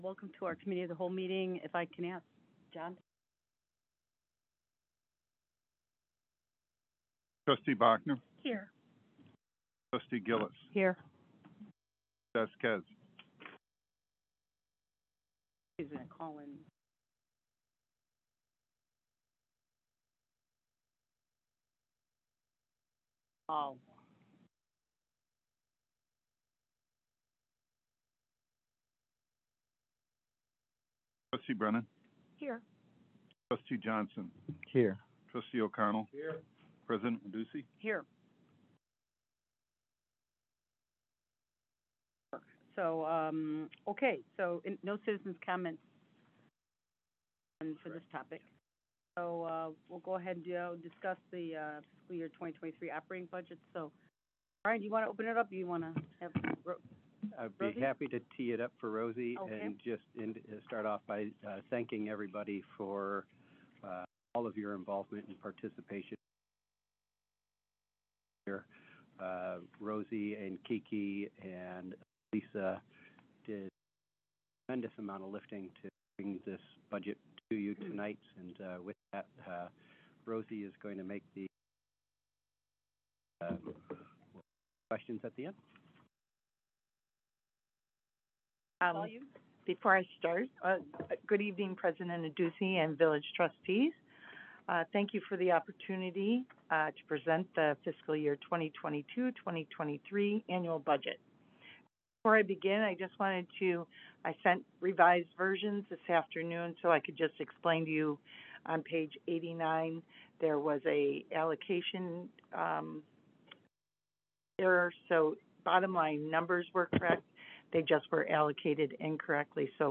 Development Review Board Meeting